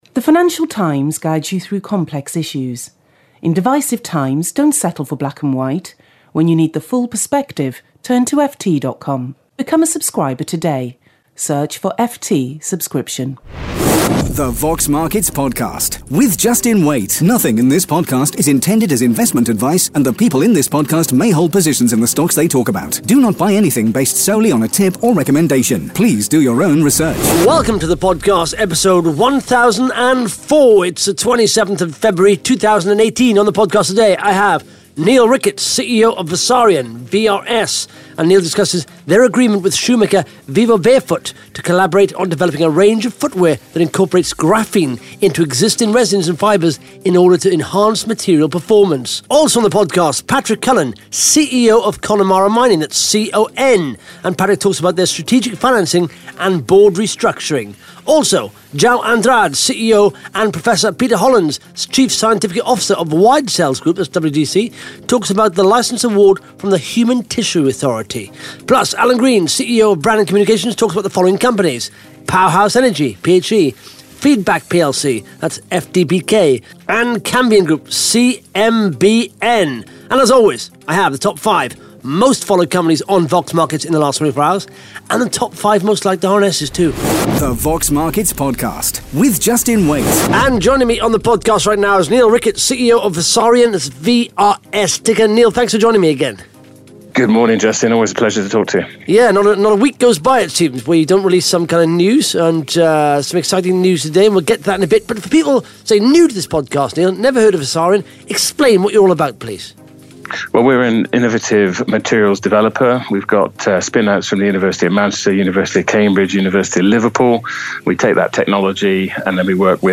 (Interview starts at 1 minute 27 seconds)